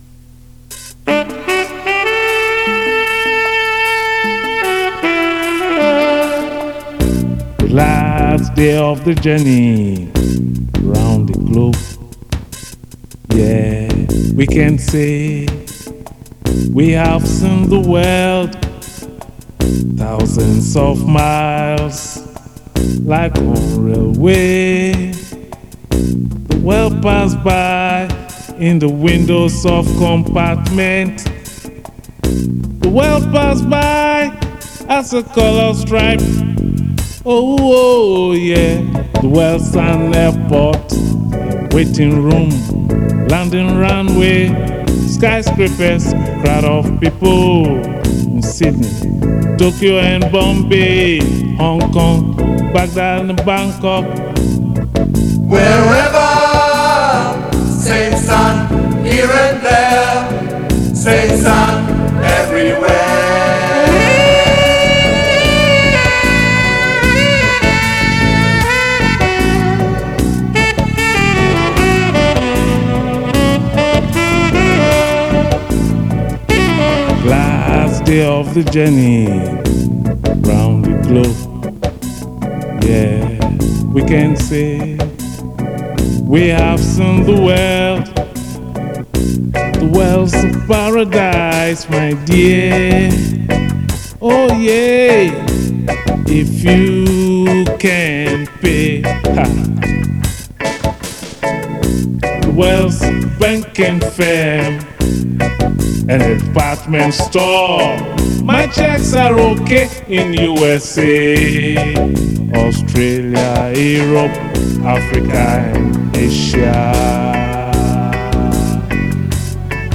git, keyboards
vocals
sax